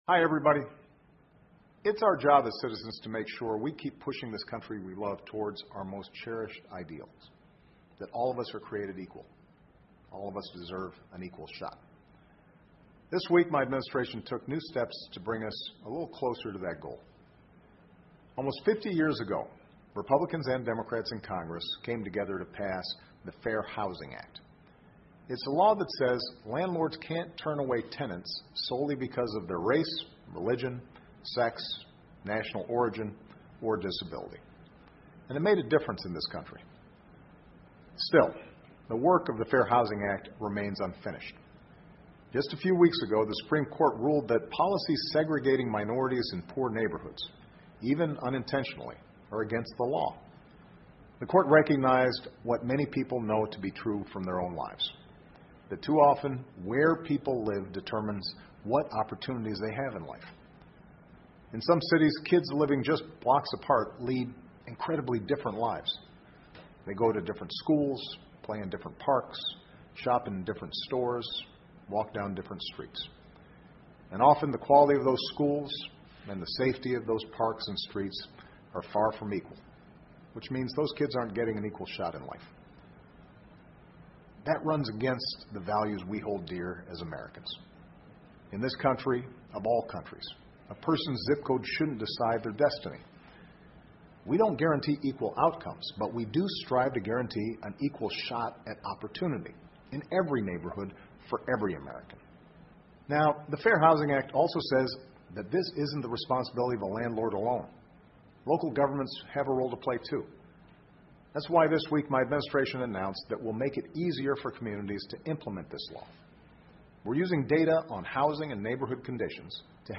奥巴马每周电视讲话：总统呼吁公平住房体系 听力文件下载—在线英语听力室